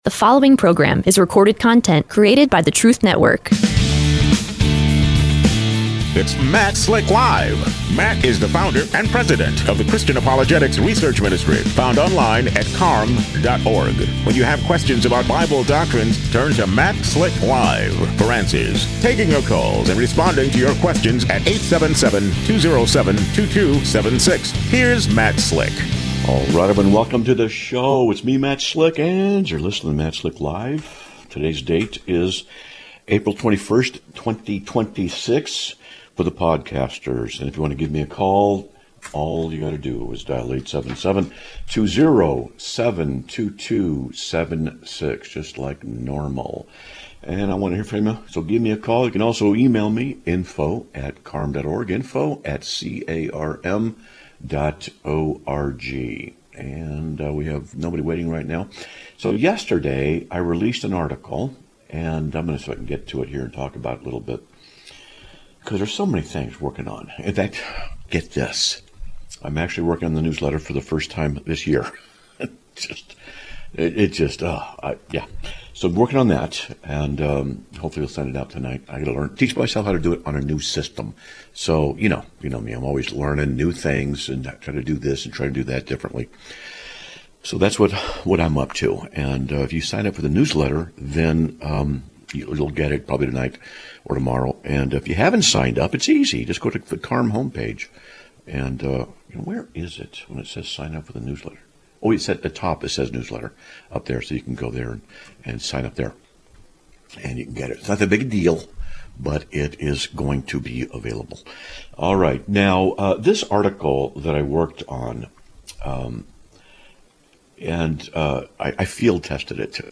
Live Broadcast of 04/21/2026